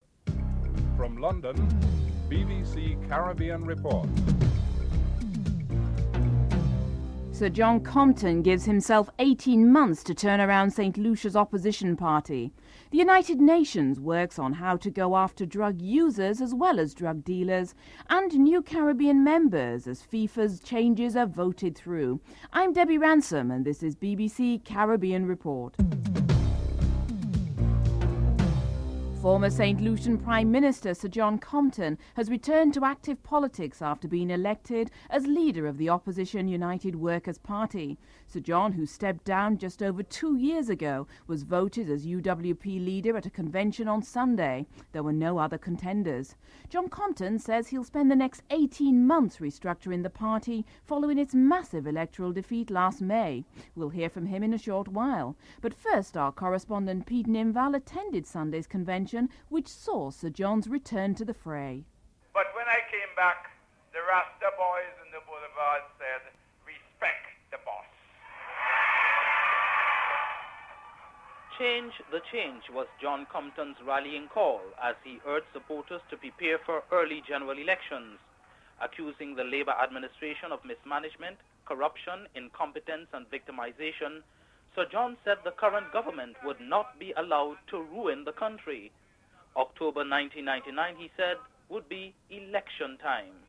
4. UN Secretary-General Kofi Annan addresses the world's largest anti-drug summit which aims to reduce both the production of and demand for illegal drugs (07:21-10:12)
Interview with Barbados Prime Minister Owen Arthur (10:13-12:13)